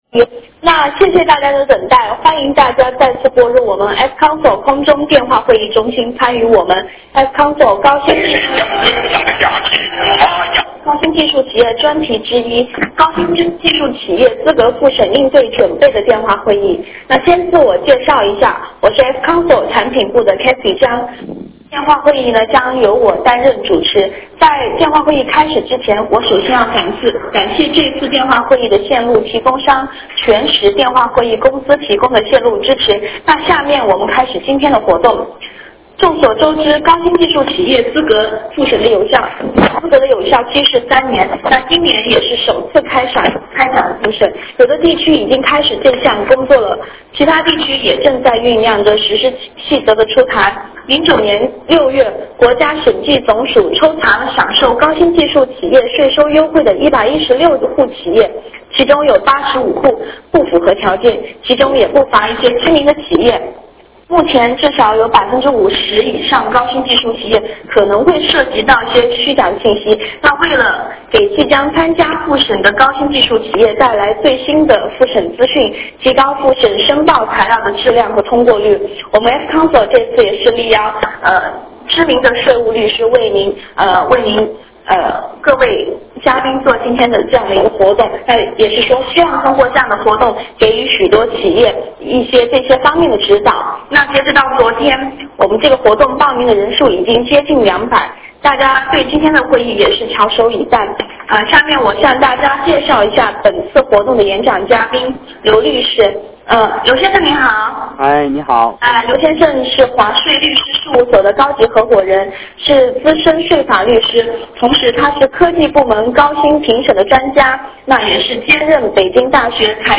电话会议
互动体验：资深税务律师直击重点，电话连线答疑解惑，及时解决您的问题 09年6月国家审计署抽查了享受高新技术企业税收优惠的116户企业，竟有85户不符合条件的企业享受税收优惠36.31亿元（不合格率73.28%），其中不乏知名企业。